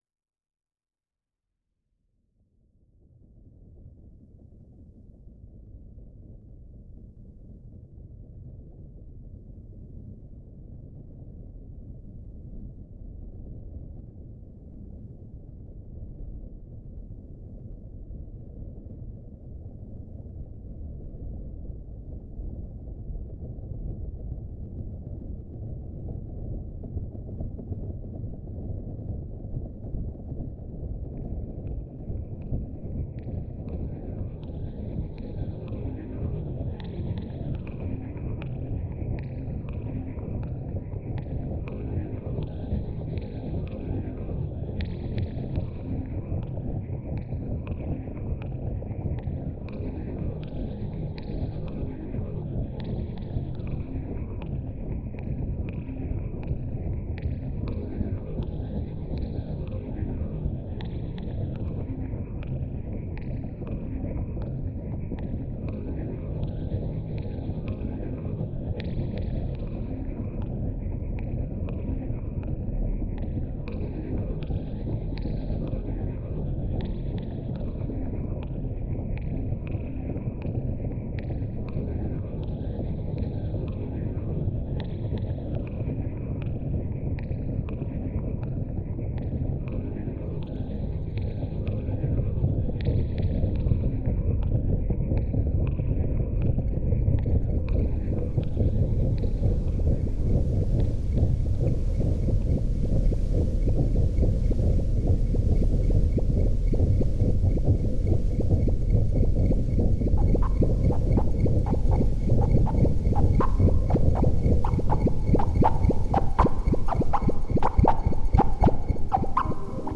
This piece is based on a field recording of a "casserole protest" ( or cacerolazo ) in Montréal, Canada. It starts with a crescendo and ends with a diminuendo (both of a sort), symbolising the growing groundswell of a popular movement, its climax in protest and then its eventual fading away (or not).
Having heard some of the contributions to Cities & Memory's "Sacred Spaces" project that used similar techiniques, I was keen to feature manipulation of the tempi of the raw material at the heart of the piece.
Most of the audio you will hear is the field recording in its original form or various "re-imaginings".